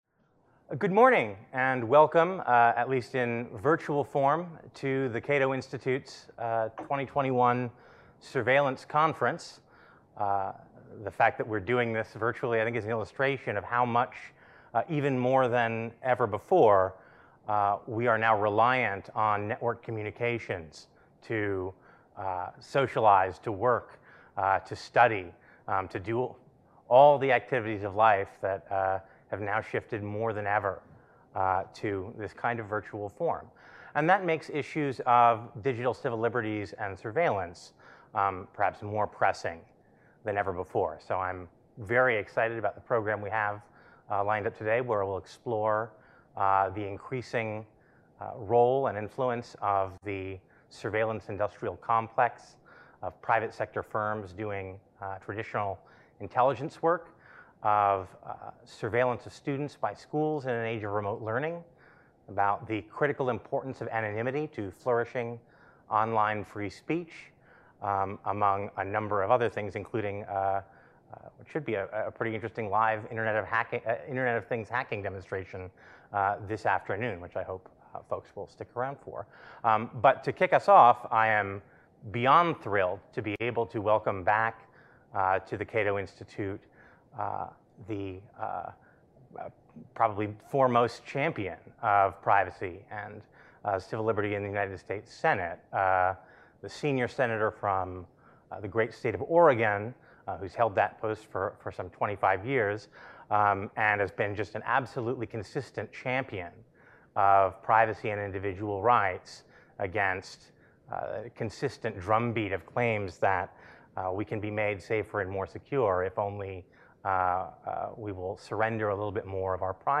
kicking off with a keynote address from Sen. Ron Wyden (D‑OR)